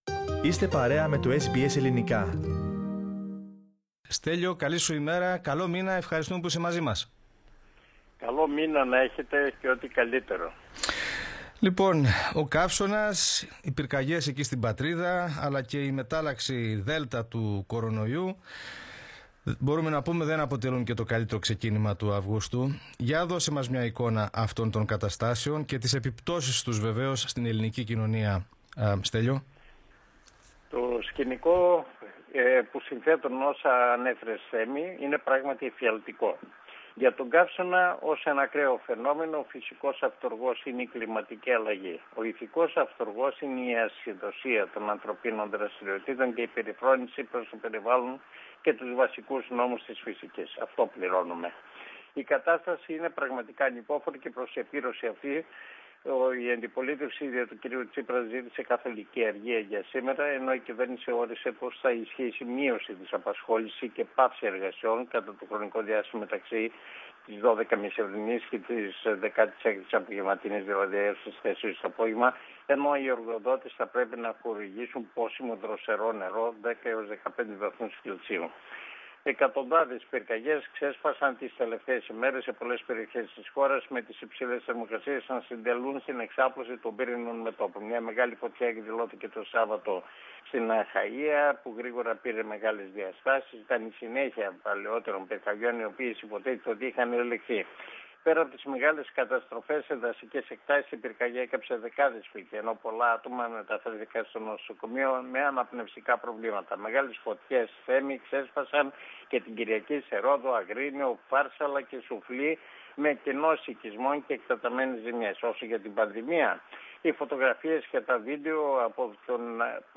Πατήστε PLAY πάνω στην εικόνα για να ακούσετε την ανταπόκριση του SBS Greek/SBS Ελληνικά.